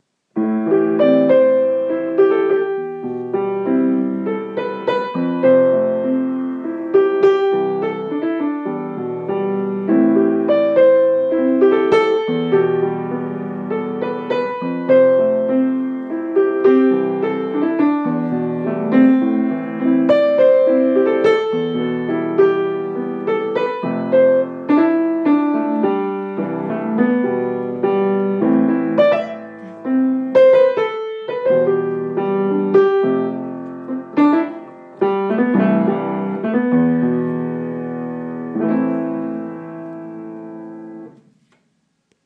Melody in A-minor